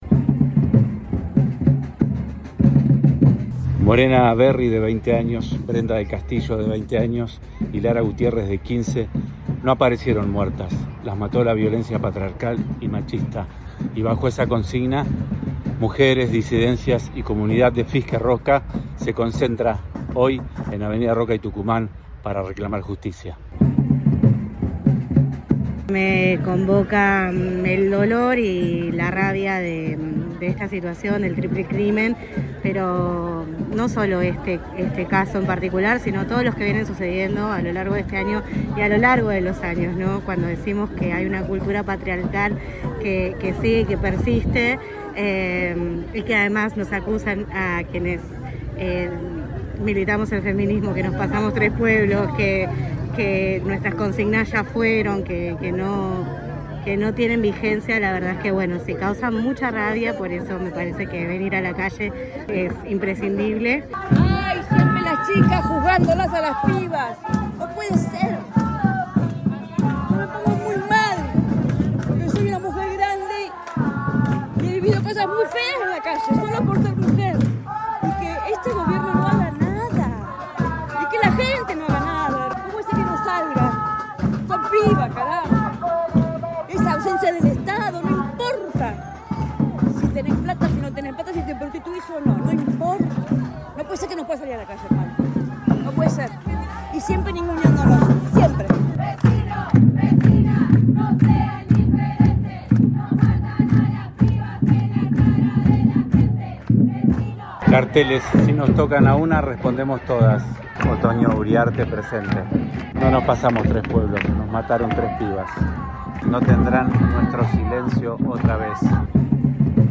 Compartimos la crónica sonora de la jornada.